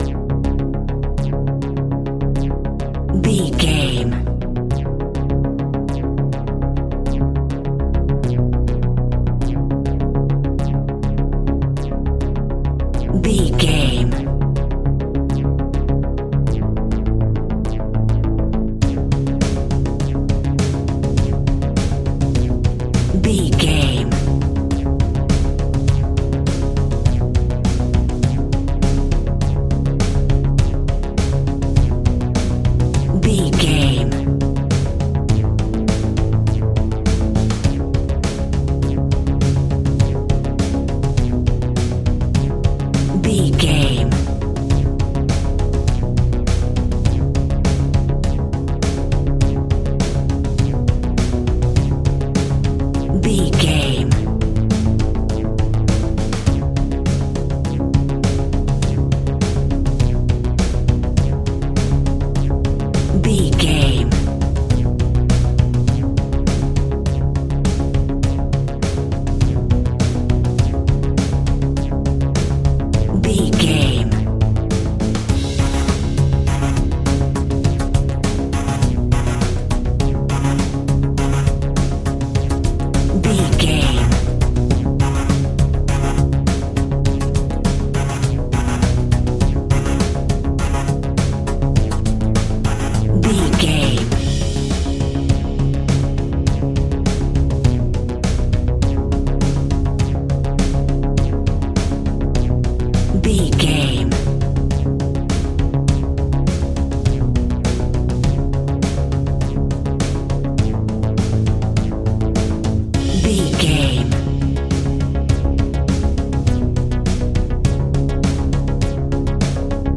Aeolian/Minor
D♭
piano
synthesiser